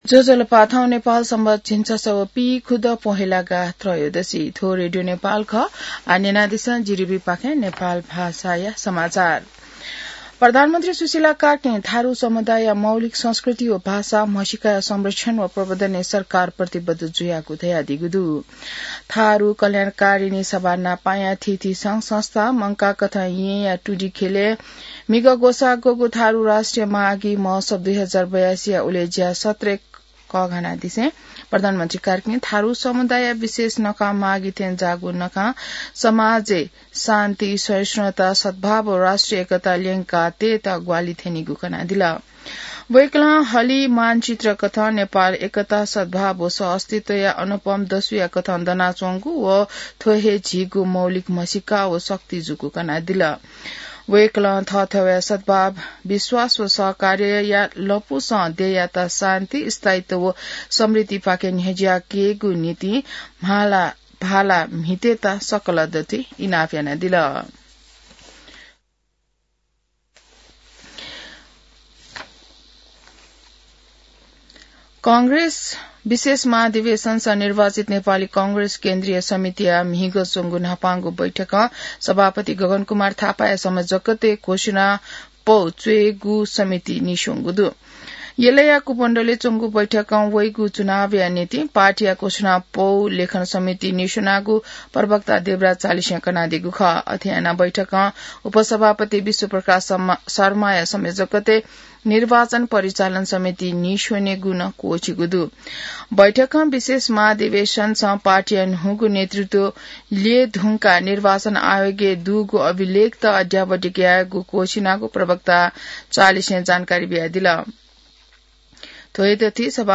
नेपाल भाषामा समाचार : २ माघ , २०८२